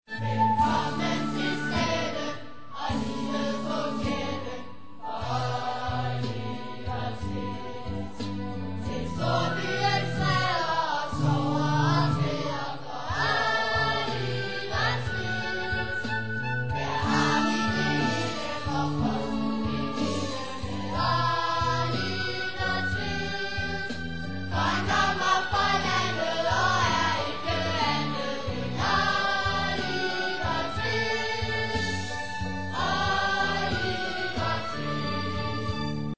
Vi hører det ene store svingende nummer efter det andet.
"live on stage!"
Finale-kor